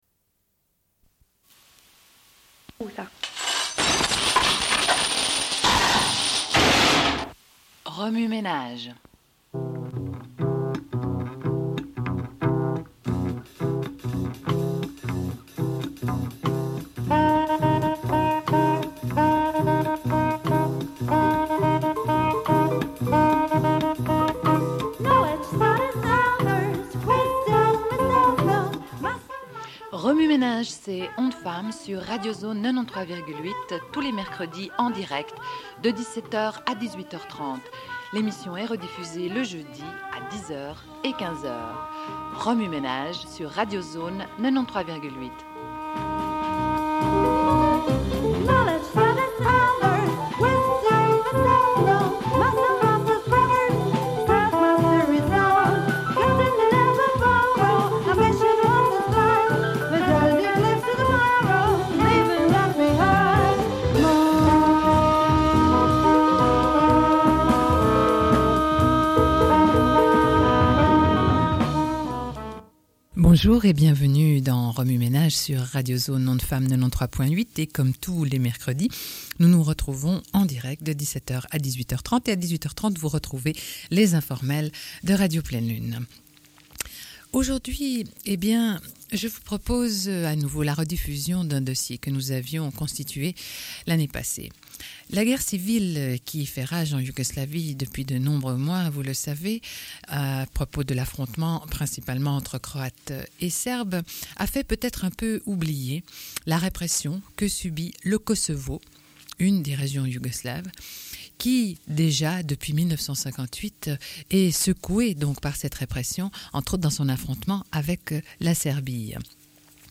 Une cassette audio, face A00:31:24